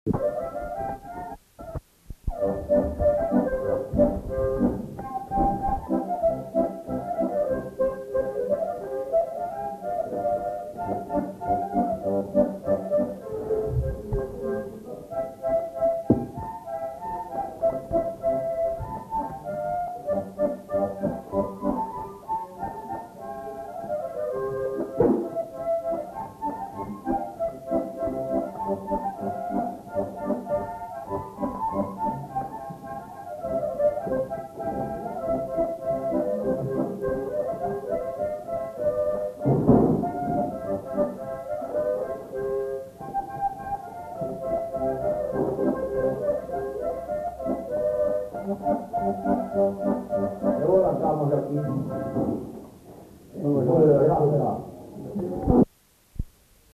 Aire culturelle : Gabardan
Lieu : Estigarde
Genre : morceau instrumental
Instrument de musique : harmonica ; accordéon diatonique
Danse : polka